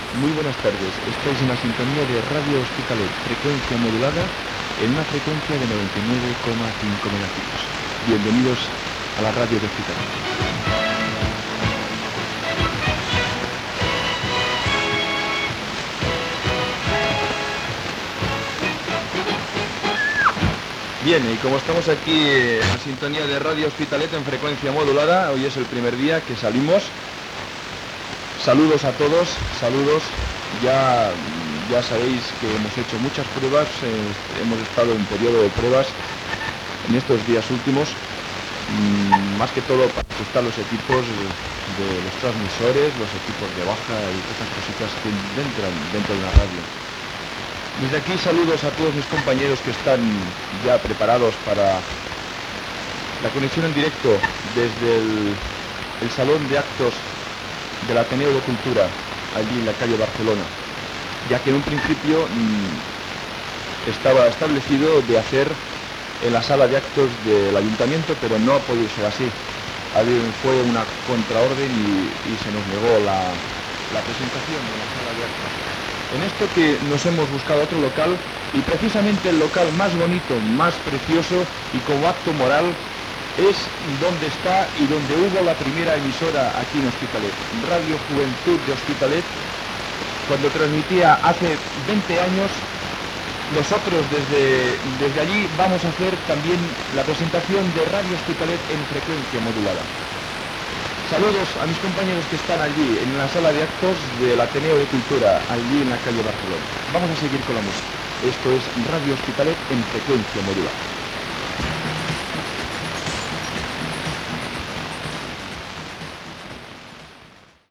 Identificació, primer dia d'emissió després del període de proves. A l'espera de connectar amb el Saló d'actes de l'Ateneu de Cultura per fer la inauguració després del període de proves.
Qualitat de l'àudio definicient